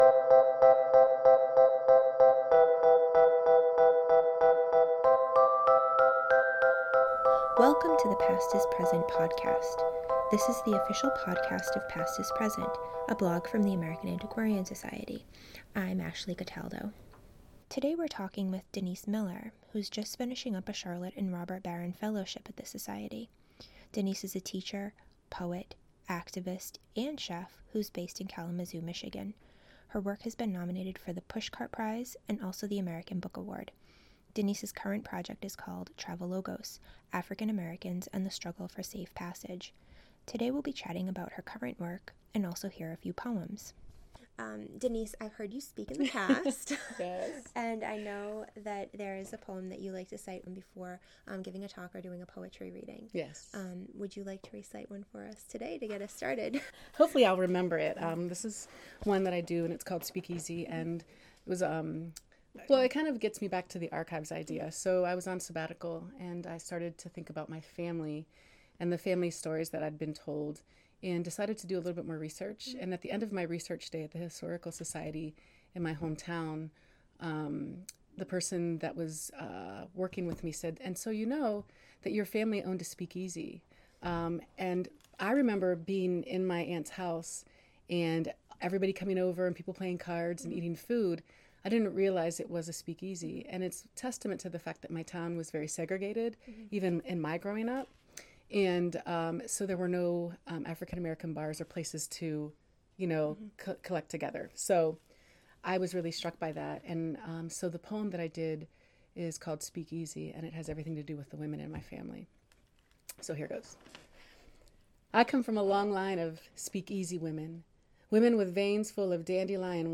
Anyone looking for a new history podcast will want to subscribe to these half-hour interviews.